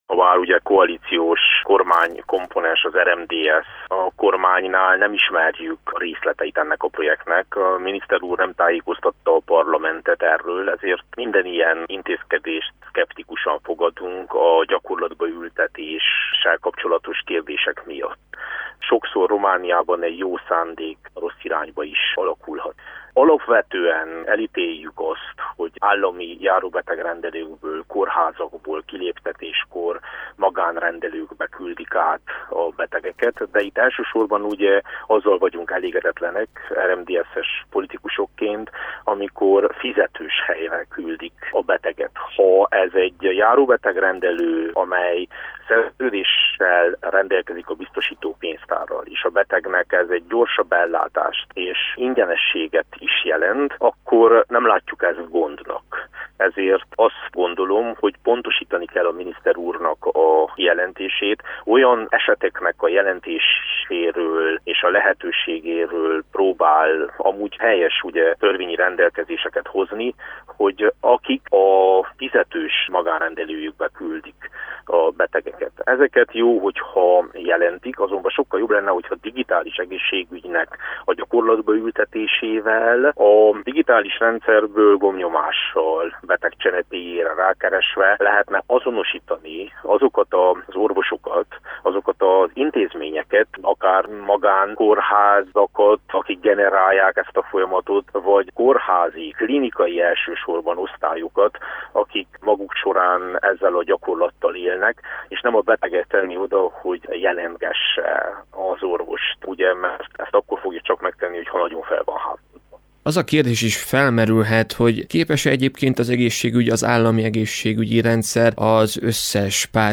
"Még kérdéses a projekt gyakorlatba ültetése, ezért szkeptikusan fogadjuk" - mondta Vass Levente, a képviselőház egészségügyi bizottságának alelnöke.
A Kolozsvári Rádiónak nyilatkozva a képviselőház egészségügyi bizottságának alelnöke, Vass Levente megjegyezte, hogy Alexandru Rogobete miniszter még nem tájékoztatta a kormányt a részletekről.